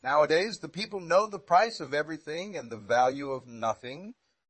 tortoise-tts-v2 like 227